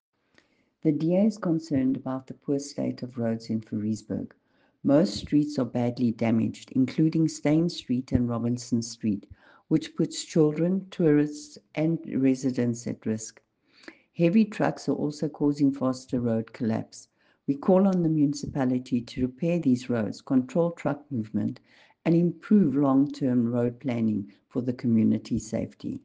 Afrikaans soundbites by Cllr Irene Rügheimer and